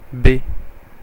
Ääntäminen
Ääntäminen France (Paris): IPA: [be] Haettu sana löytyi näillä lähdekielillä: ranska Käännöksiä ei löytynyt valitulle kohdekielelle.